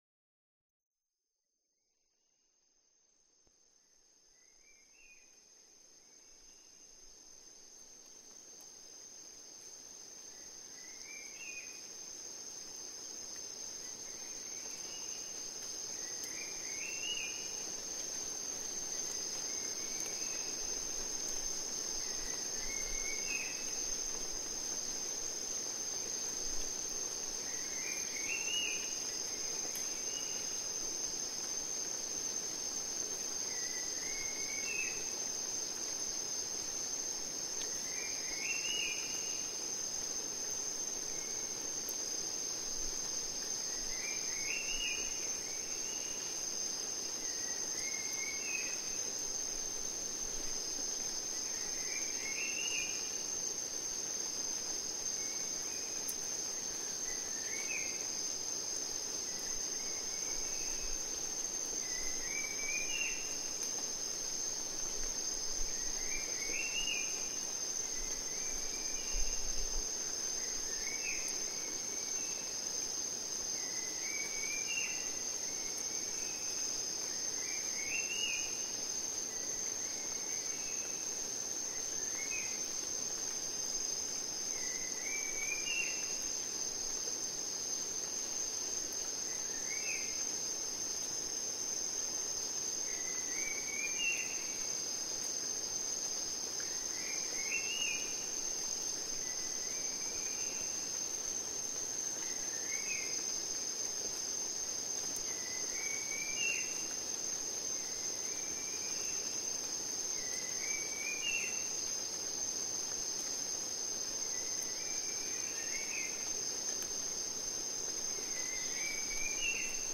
Recorded in the rainforests of Buton Island, Sulawesi, Indonesia.
LISTEN TO THIS SOUNDSCAPE 1 hour Share Download Here is a one-hour immersive soundscape captured in the lush rainforest of Buton Island, Sulawesi, Indonesia. Close your eyes and let the layered symphony of the tropics transport you: the distant calls of vibrant birds mingle with the rustle of leaves in the humid breeze, occasional insect choruses, and the subtle movements of wildlife hidden in the canopy. Among the voices you'll hear are the distinctive chatter of the Sulawesi Babbler, the bold crowing of Red Junglefowl, the melodic trills of Pale-blue Monarch and Grosbeak Starling, the soft coos of Black-naped Fruit Dove and Pale-bellied White-eye, the resonant honks of the majestic Knobbed Hornbill, and even the faint nocturnal stirrings of the elusive Spectral Tarsier.
indonesian-rainforest.mp3